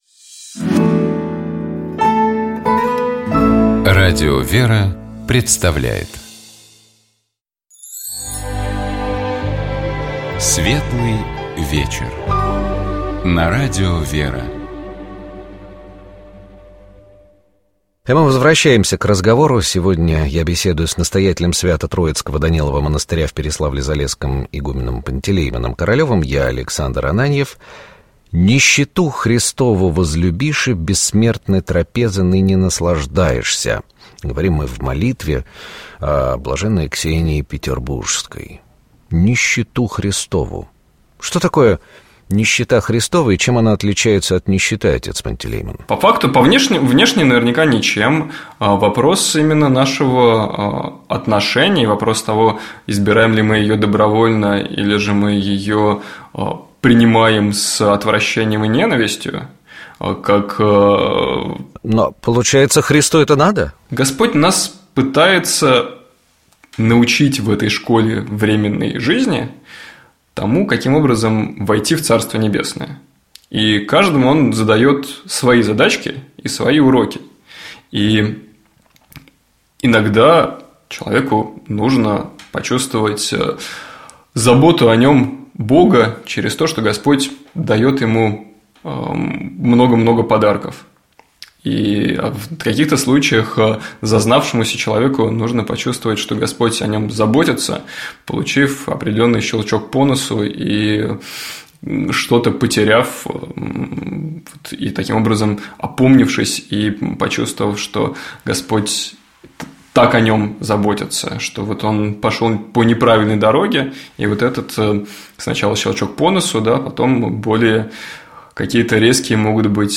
Наш собеседник